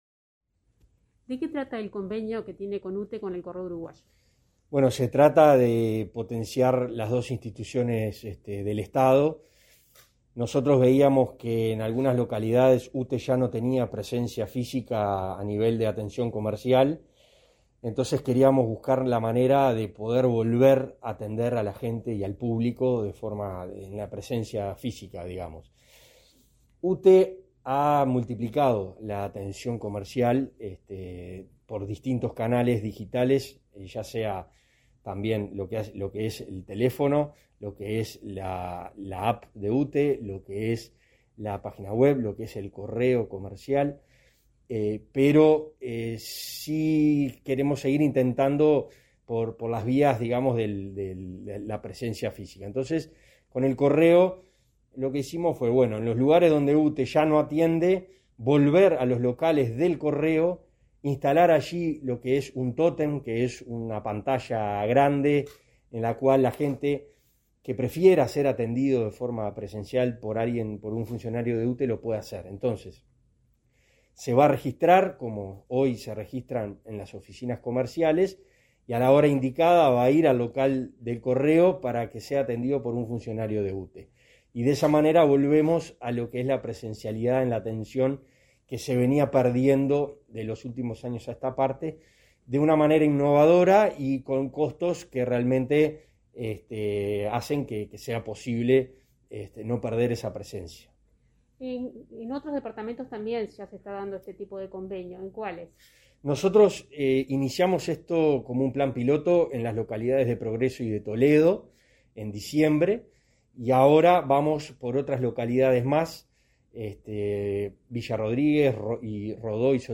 Entrevista al director UTE, Felipe Algorta
Entrevista al director UTE, Felipe Algorta 02/02/2022 Compartir Facebook X Copiar enlace WhatsApp LinkedIn La empresa UTE brindará servicio comercial mediante dispositivos de videollamadas (tótems) en localidades de menos de 2.000 habitantes, a fin de mantener la atención en todo el interior del país. En entrevista con Comunicación Presidencial, el director del ente detalló la iniciativa.